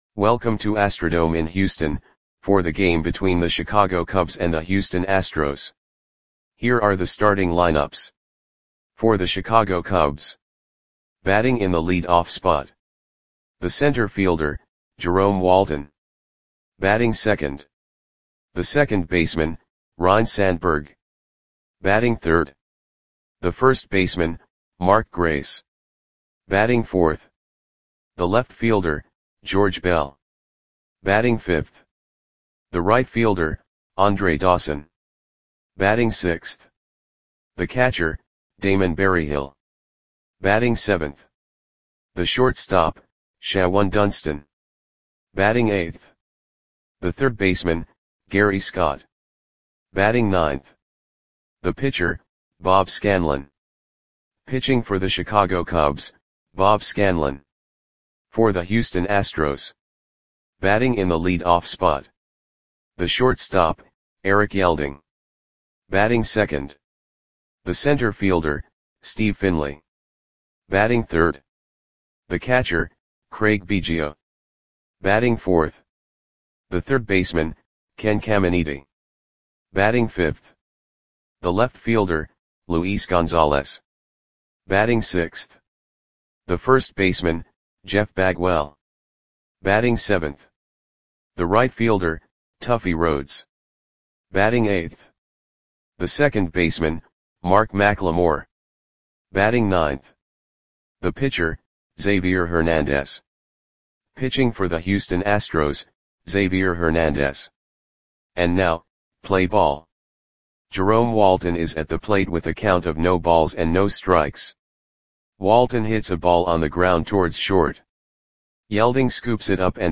Click the button below to listen to the audio play-by-play.
Cubs 4 @ Astros 3 AstrodomeMay 7, 1991 (No Comments)